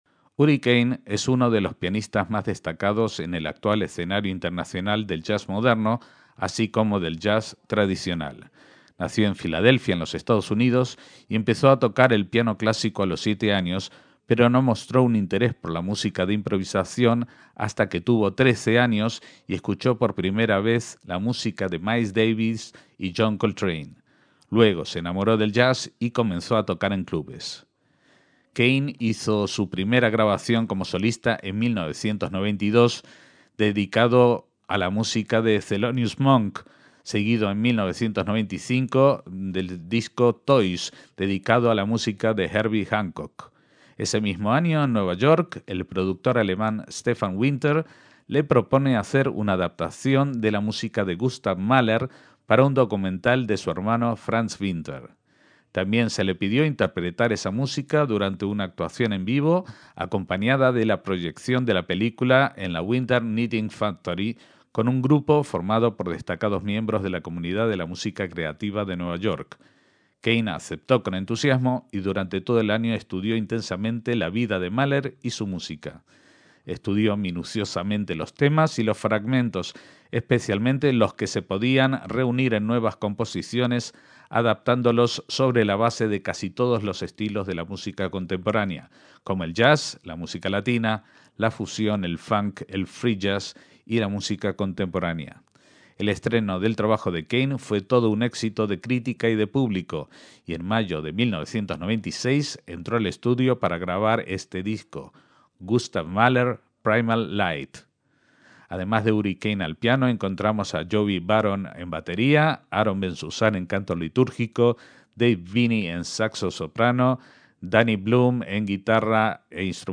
trompeta
trombón
saxo soprano
clarinete
violín
chelo
guitarra
tocadiscos
bajo
batería
pandero y canto litúrgico